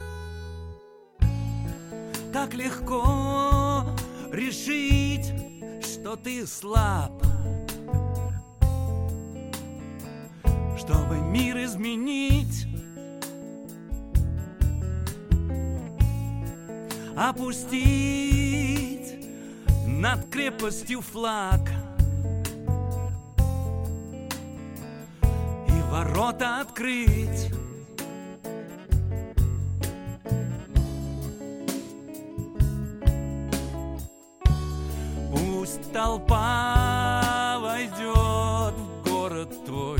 спокойные , рок , ретро